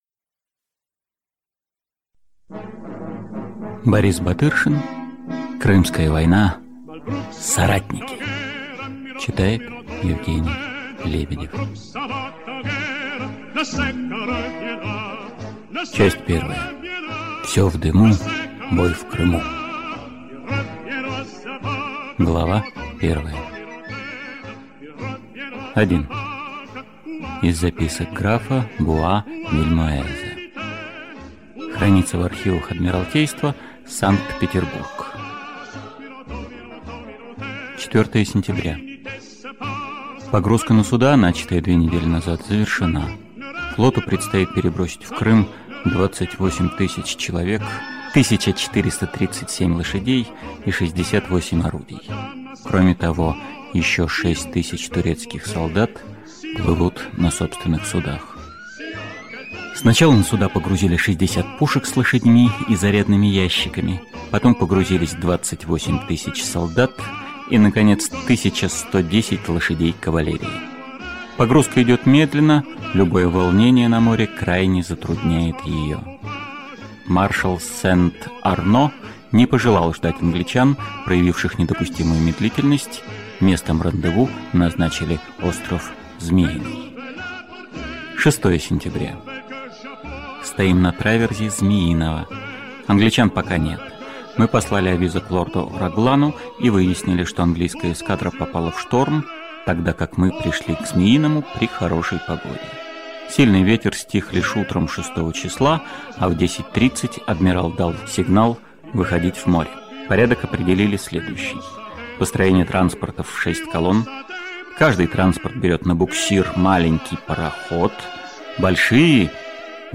Аудиокнига Крымская война. Соратники | Библиотека аудиокниг